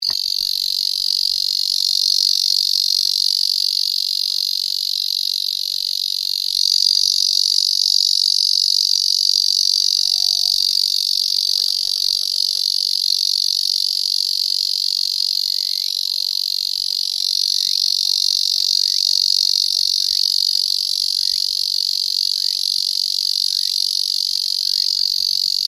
エゾゼミ
エゾゼミの鳴き声はこちら
ミンミンゼミやツクツクボウシのように明らかに違う鳴き方をしてくれれば素人の私にでも分かるのですが、 コエゾゼミとエゾゼミは地味なよく似た鳴き方をします。
塩塚高原　８月２８日